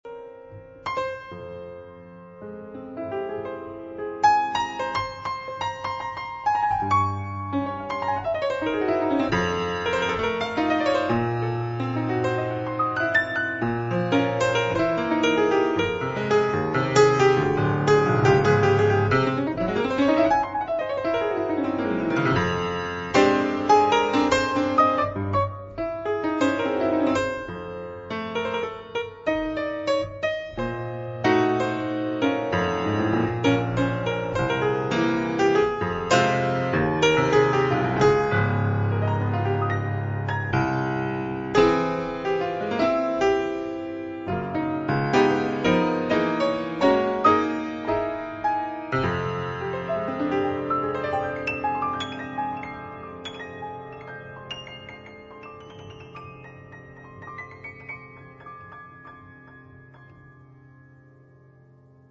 piano
soprano saxophone